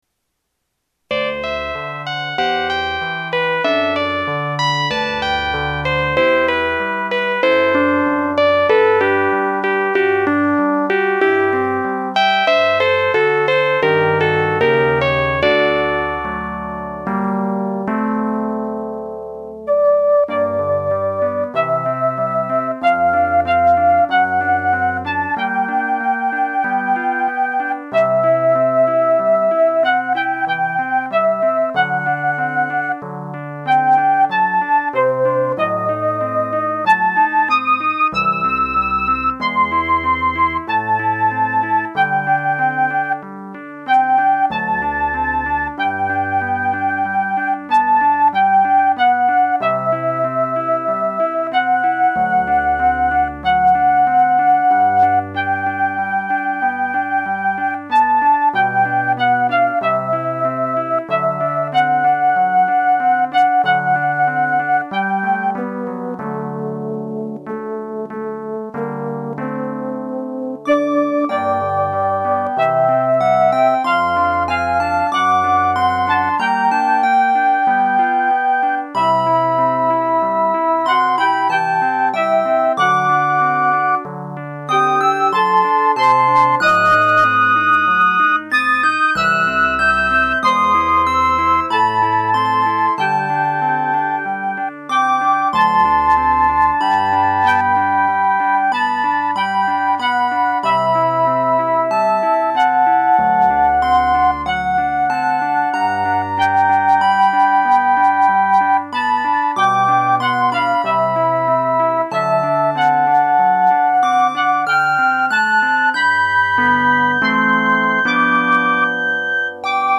[For more new midis in classical styles, click here]